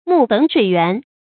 木本水源 注音： ㄇㄨˋ ㄅㄣˇ ㄕㄨㄟˇ ㄧㄨㄢˊ 讀音讀法： 意思解釋： 樹的根本，水的源頭。比喻事物的根本或事情的原因。